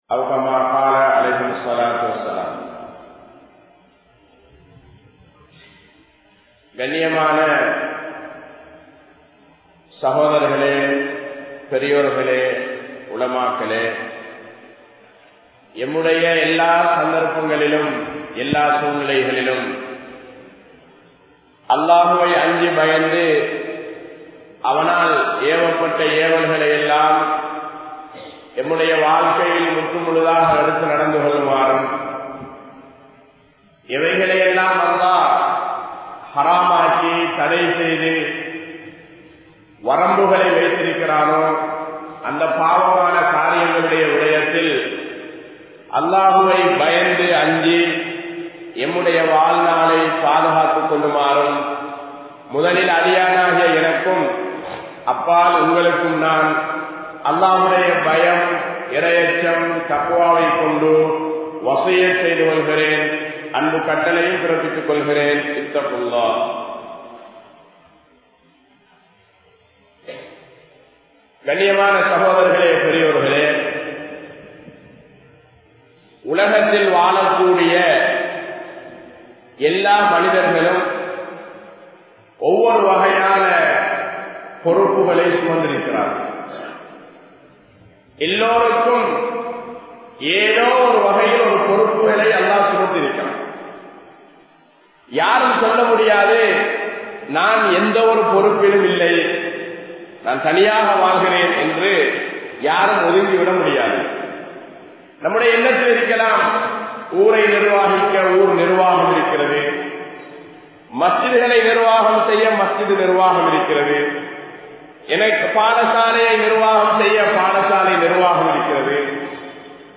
Naahareehaththin Peyaral Maarkaththai Ilanthu Vittoam (நாகரீகத்தின் பெயரால் மார்க்கத்தை இழந்து விட்டோம்) | Audio Bayans | All Ceylon Muslim Youth Community | Addalaichenai
Thambala Muhideen Jumua Masjith